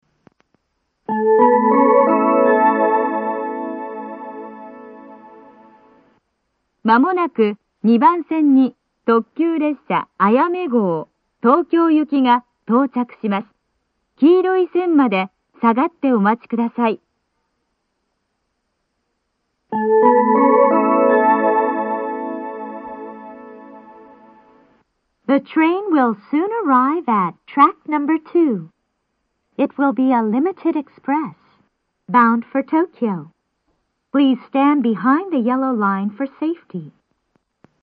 この駅は禁煙放送が流れていて、それが被りやすいです。
２番線接近放送 特急あやめ号東京行の放送です。